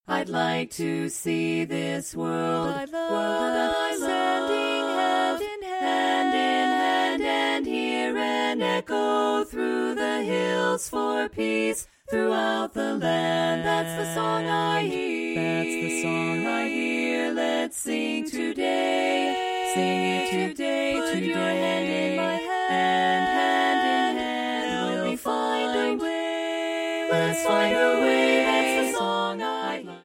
70s pop song in 8 voice parts